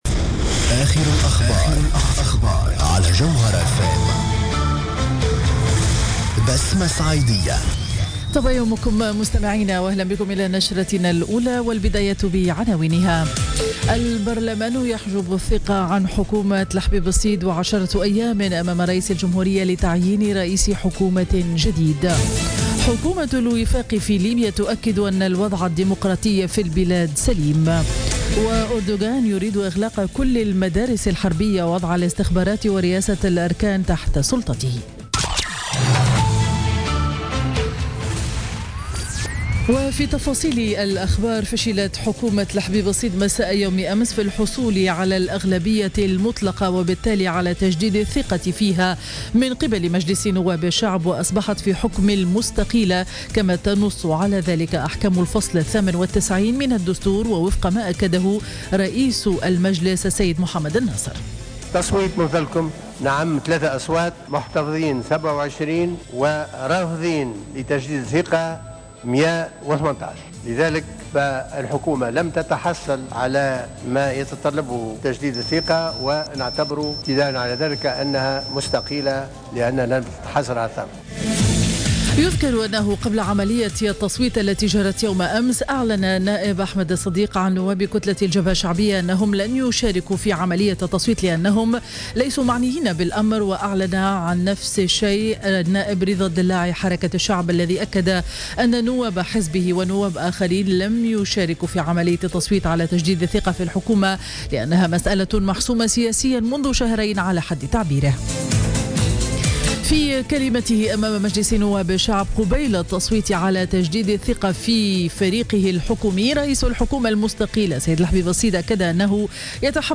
نشرة أخبار السابعة صباحا ليوم الأحد 31 جويلية 2016